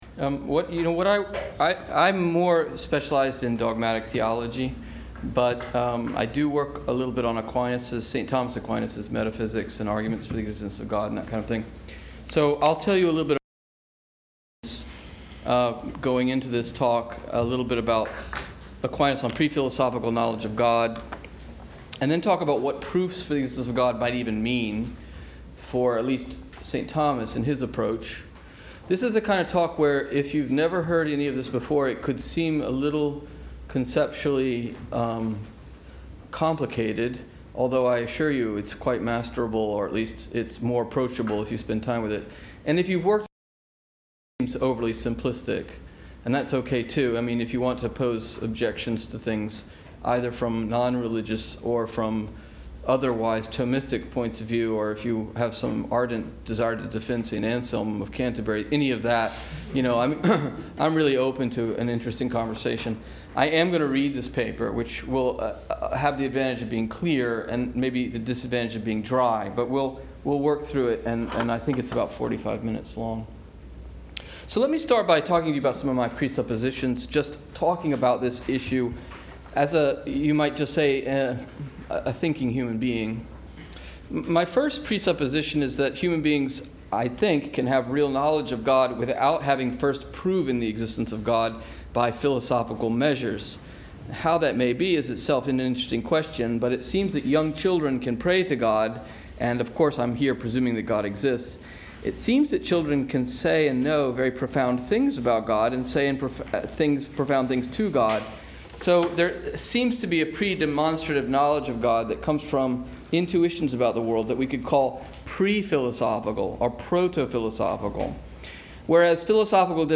This lecture was offered at Trinity College, Dublin on November 6, 2019.